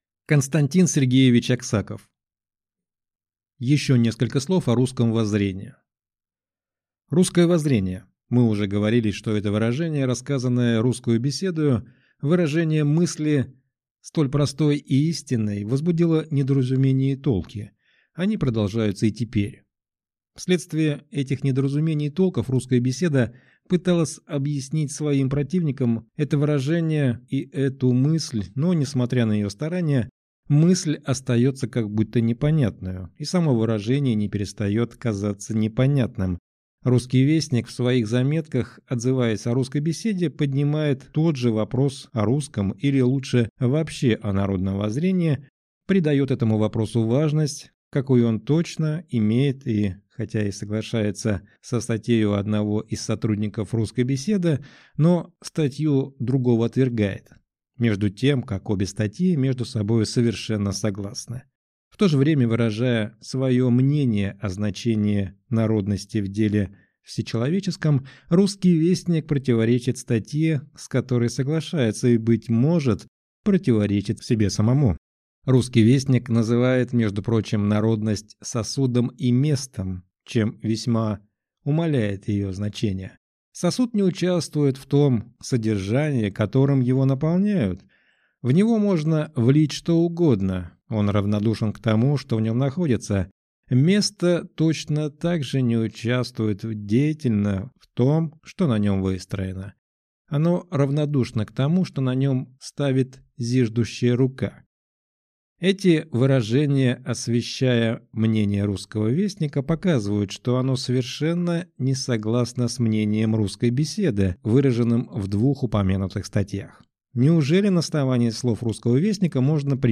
Аудиокнига Еще несколько слов о русском воззрении | Библиотека аудиокниг